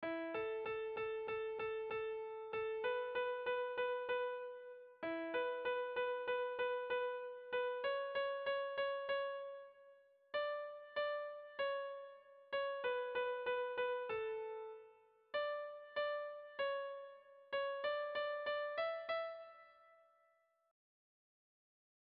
Dantzakoa
Kopla doinua
Lauko txikia (hg) / Bi puntuko txikia (ip)
A1A2